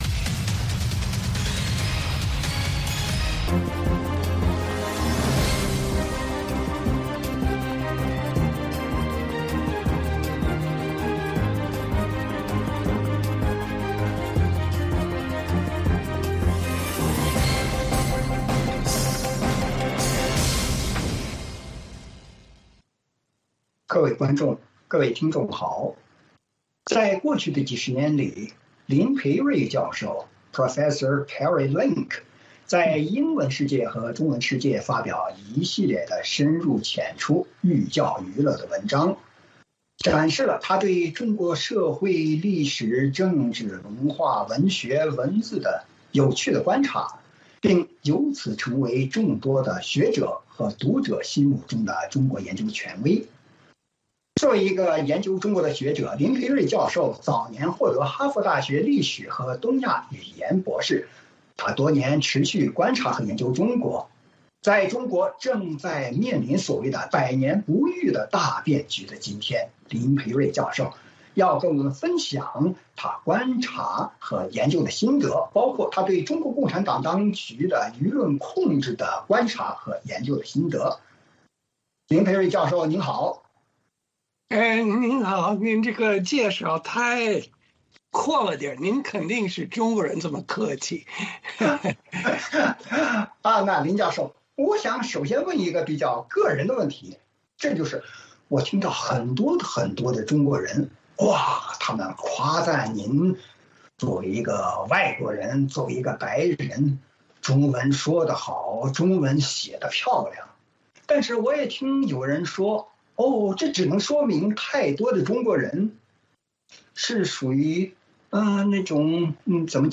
VOA卫视-时事大家谈 专访汉学家林培瑞：如何看当今中国语言文化、官民价值观对峙？